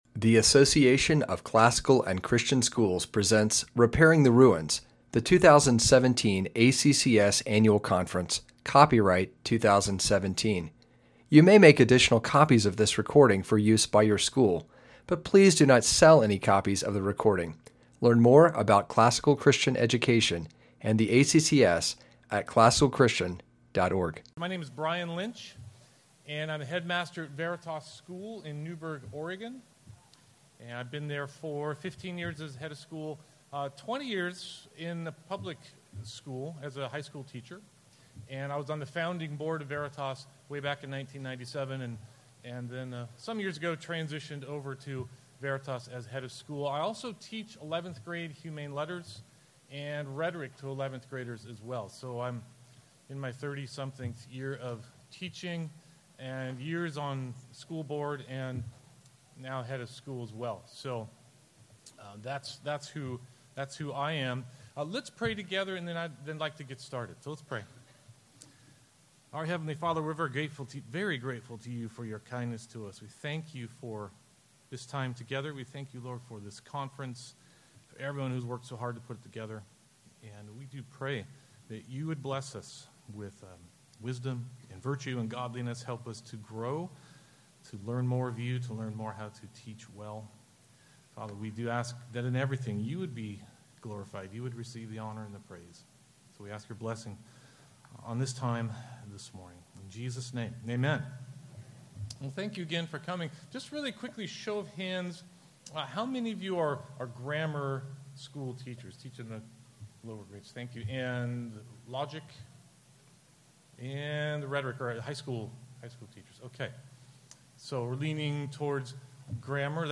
2017 Foundations Talk | 59:22:00 | All Grade Levels, General Classroom
Jan 9, 2019 | All Grade Levels, Conference Talks, Foundations Talk, General Classroom, Library, Media_Audio | 0 comments
Additional Materials The Association of Classical & Christian Schools presents Repairing the Ruins, the ACCS annual conference, copyright ACCS.